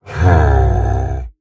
Minecraft Version Minecraft Version snapshot Latest Release | Latest Snapshot snapshot / assets / minecraft / sounds / mob / ravager / idle4.ogg Compare With Compare With Latest Release | Latest Snapshot